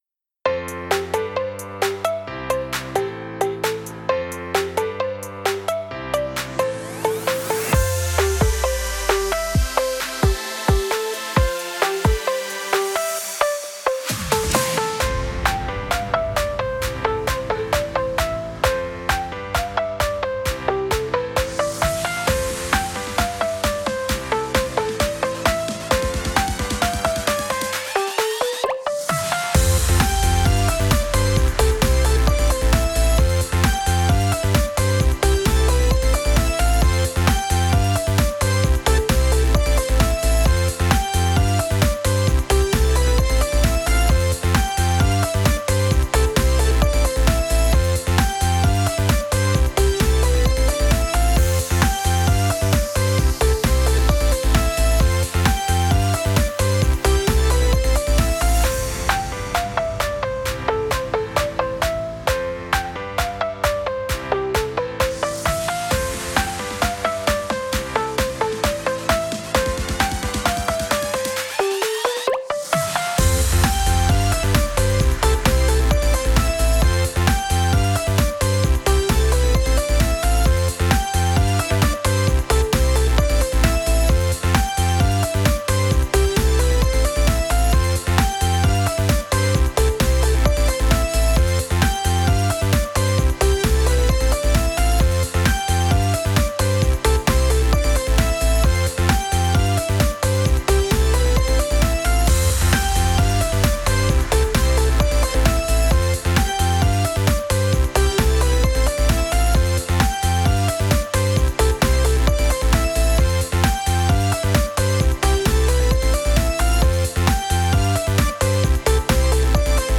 かっこいい/明るい/エンディング/EDM/かわいい
かっこよさも感じられるKawaii Future Bass BGMです。